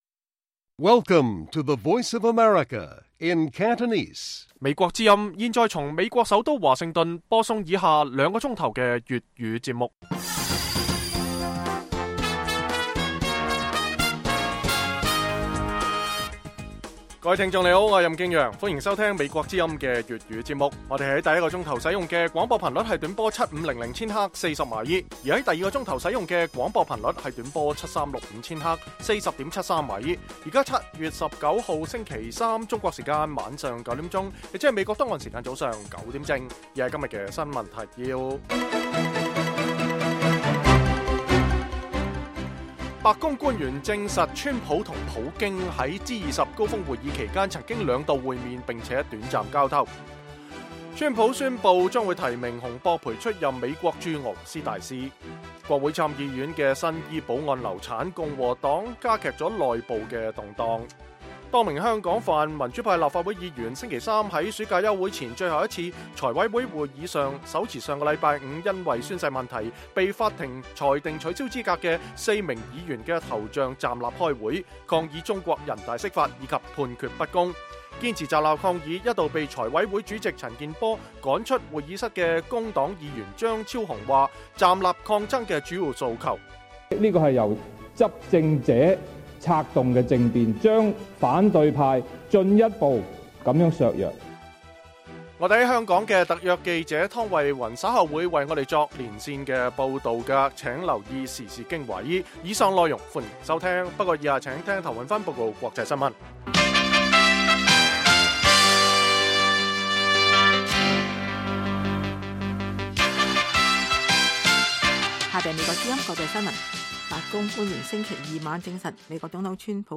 北京時間每晚9－10點 (1300-1400 UTC)粵語廣播節目。